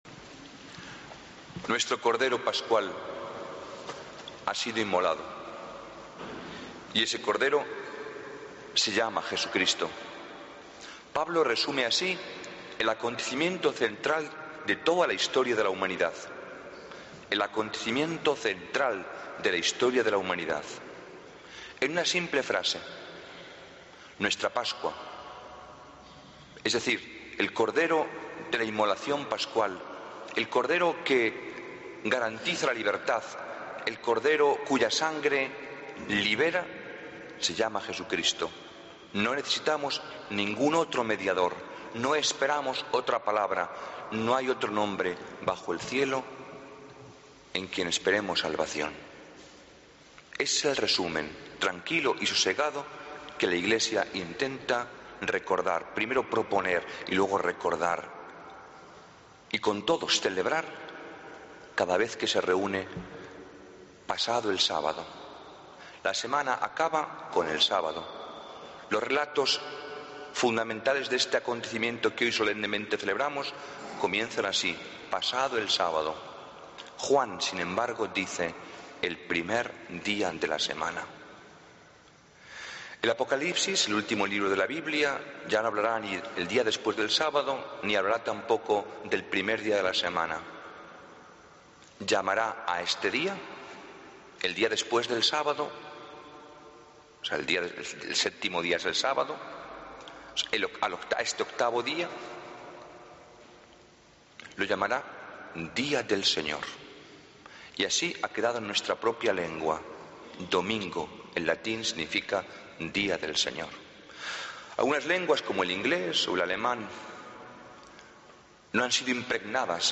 Homilía del Domingo 20 de Abril de 2014